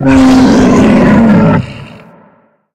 sounds / monsters / psysucker / die_2.ogg